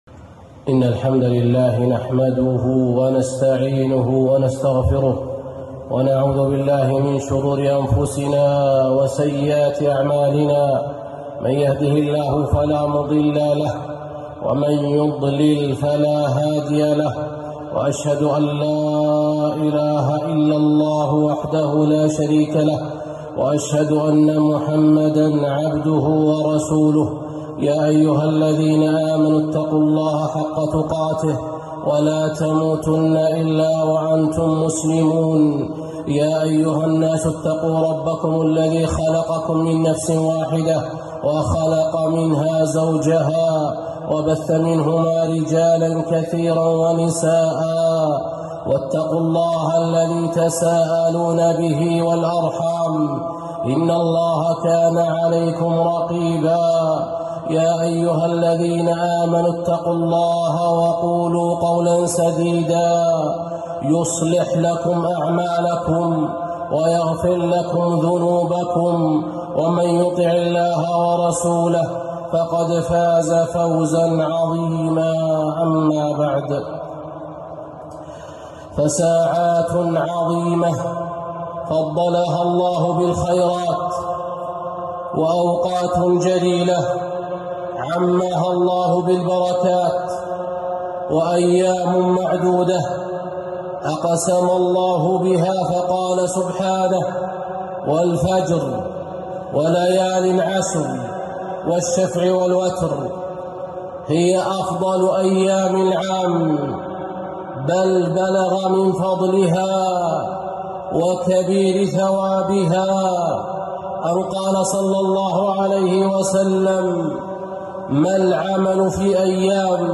خطبة - أيام العشر